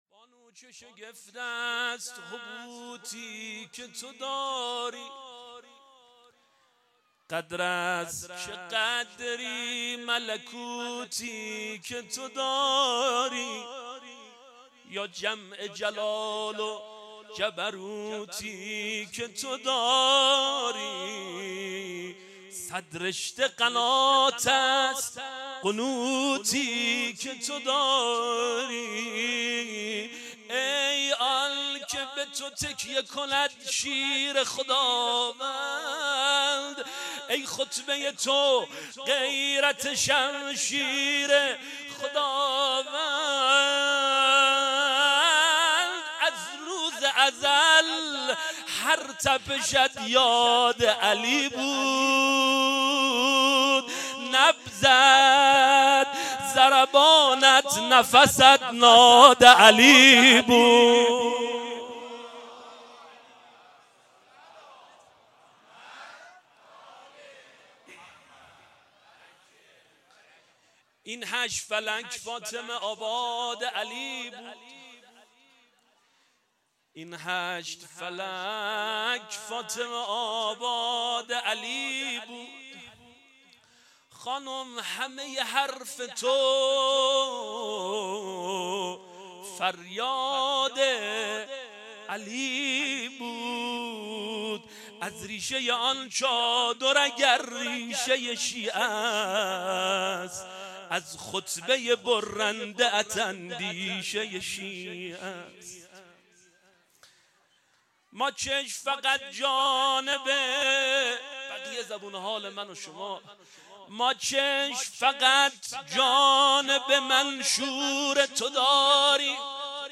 جشن کوثر ولایت15-مجمع دلسوختگان بقیع- مدح